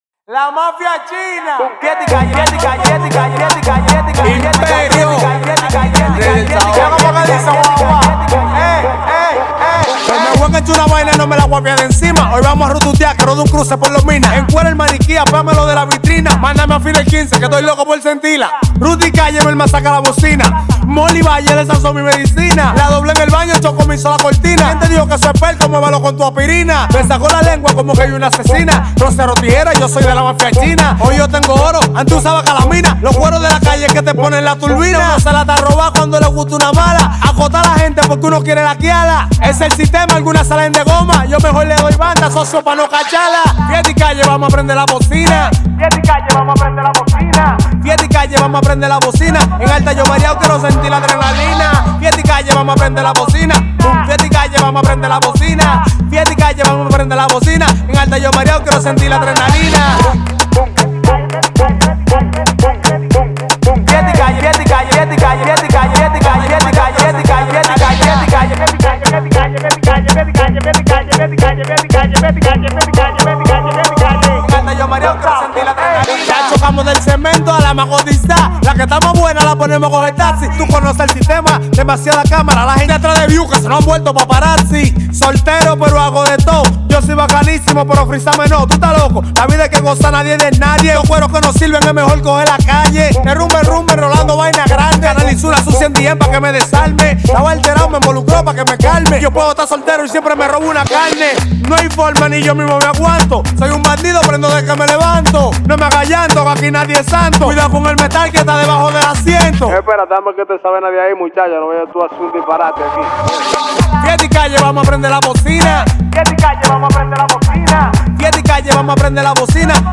Genre: Dembow.